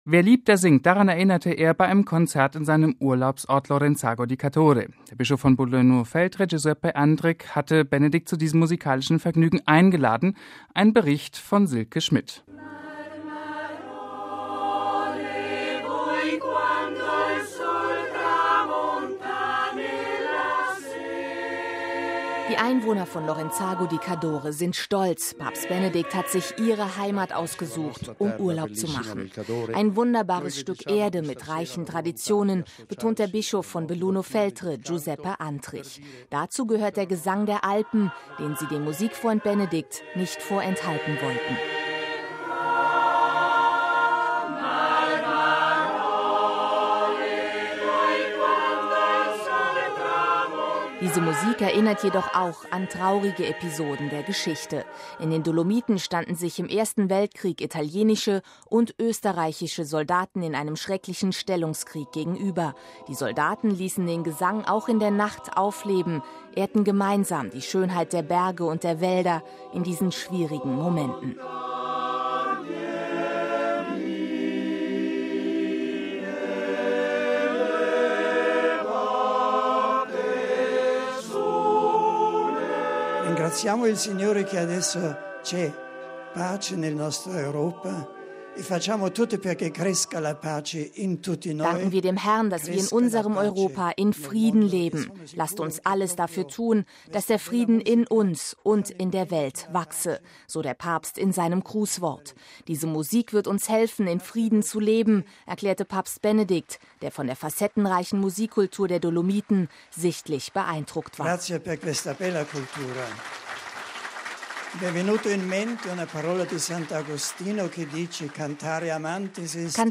MP3 „Wer liebt, der singt“ – daran erinnerte Papst Benedikt gestern bei einem Konzert in seinem Urlaubsort Lorenzago di Cadore. Der Bischof von Belluno Feltre, Giuseppe Andrich hatte Benedikt XVI. zu diesem musikalischen Vergnügen mit sieben einheimischen Chören eingeladen.